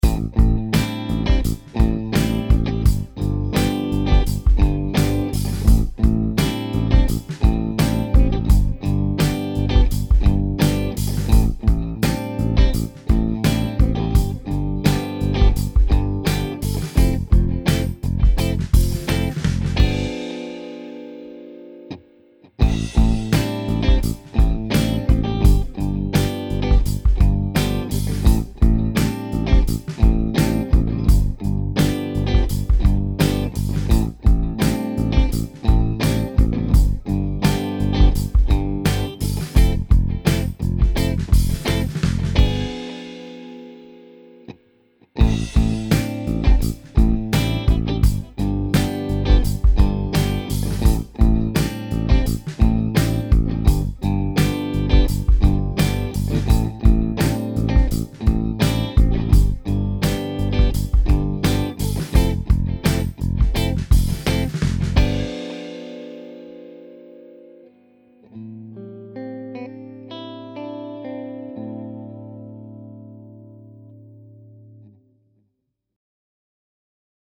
backing-track for videoDownload
backing-track.mp3